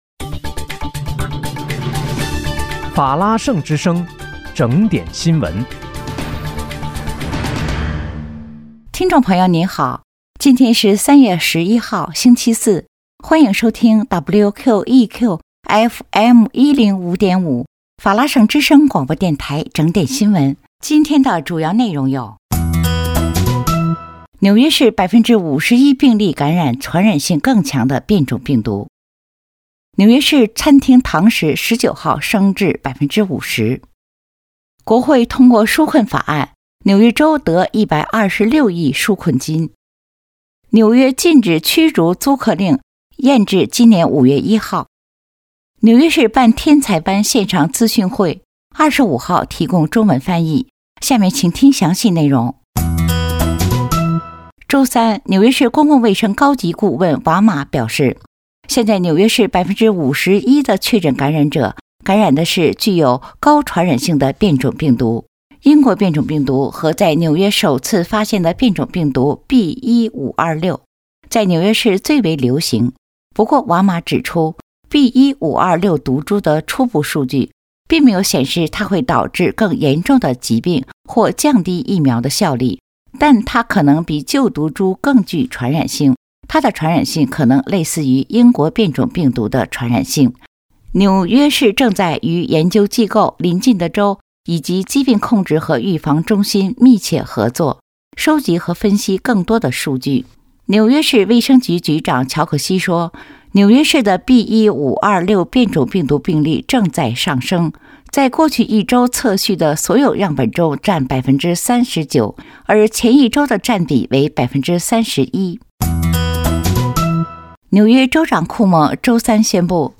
3月11日（星期四）纽约整点新闻